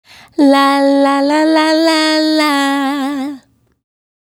La La La 110-C#.wav